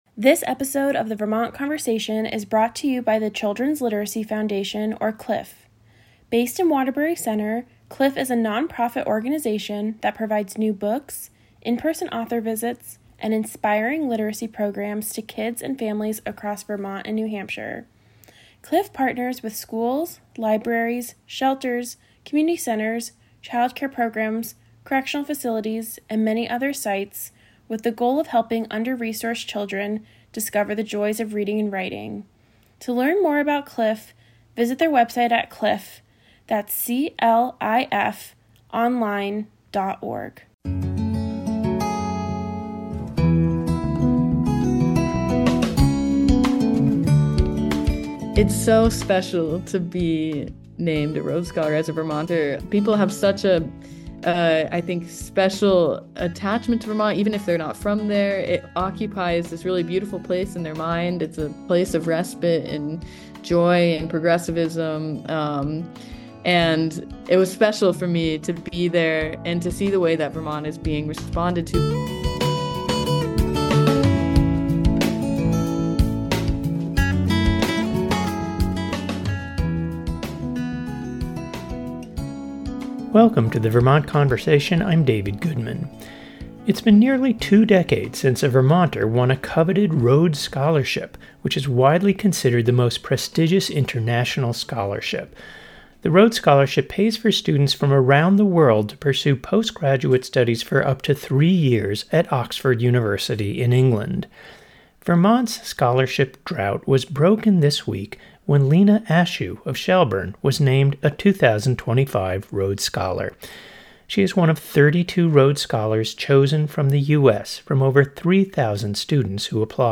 in-depth interviews on local and national issues with politicians, activists, artists, changemakers and citizens who are making a difference